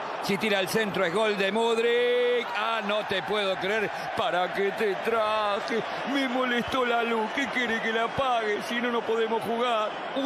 te molesto la luz Meme Sound Effect